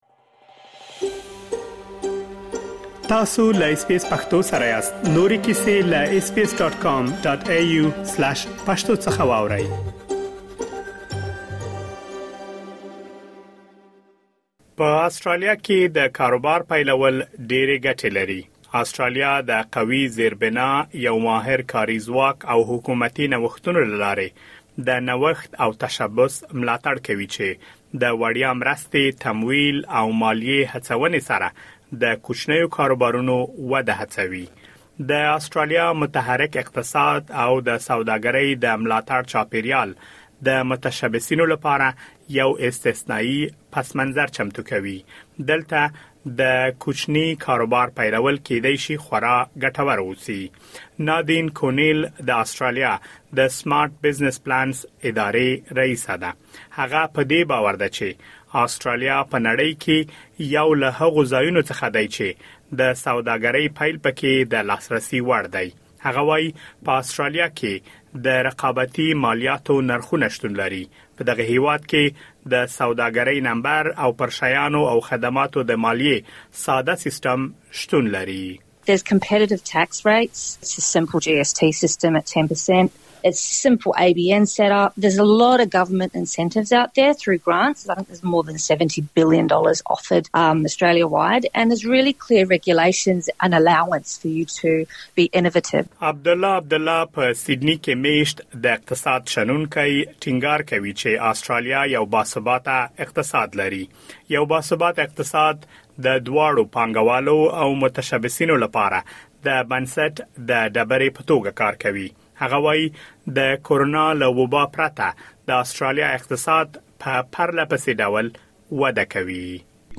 په آسټرالیا کې د خپل کاروبار پیلول ډېرې ګټې لري مګر سخت کار ته اړتیا لري. که چېرې غواړئ په آسټرالیا کې خپل کاروبار پیل کړئ نو په دې اړه مهم معلومات دلته په رپوټ کې اورېدلی شئ.